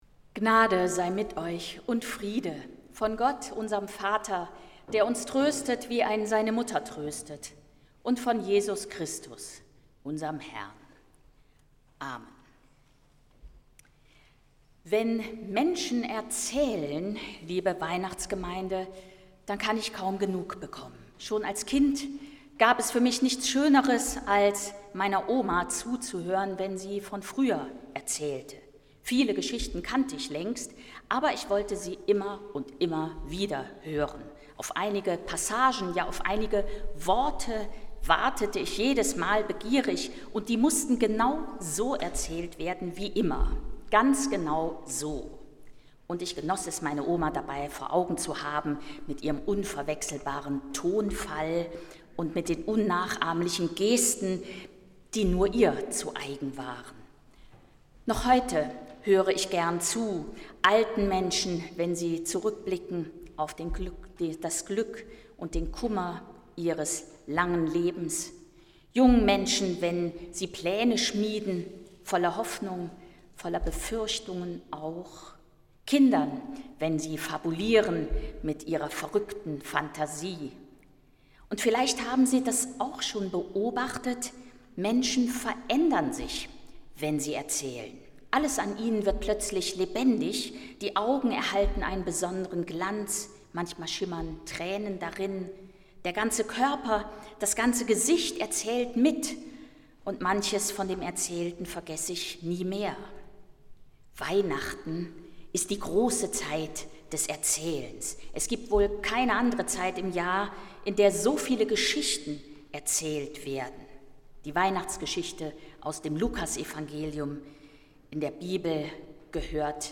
Predigt zum Heiligen Abend aus der Zionskirche in Bethel
Die Predigt von Annette Kurschus stellen wir für Sie hier noch einmal zum Nachhören bereit.